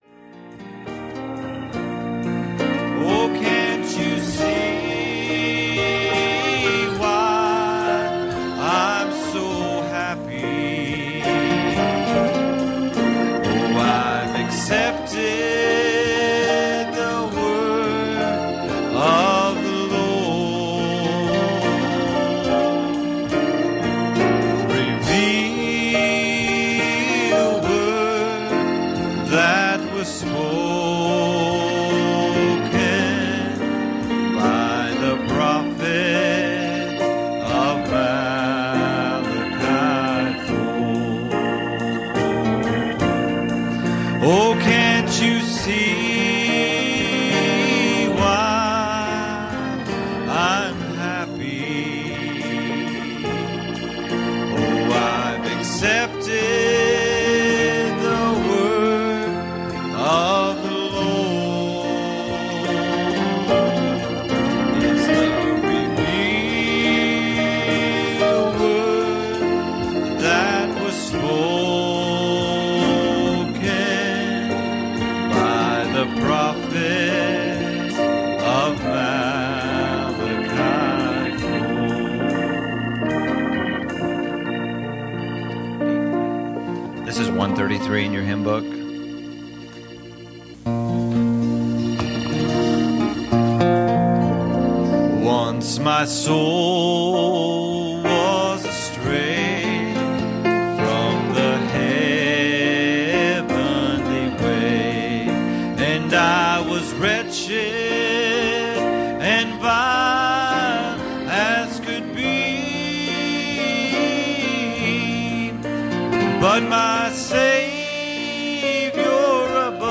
Passage: Exodus 15:20 Service Type: Wednesday Evening